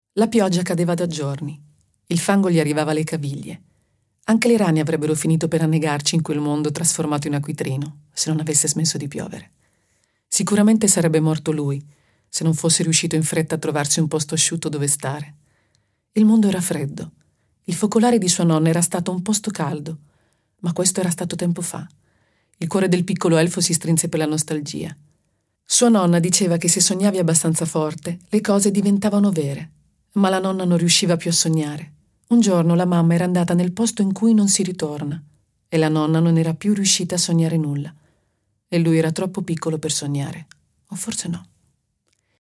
letto da Mietta
Versione integrale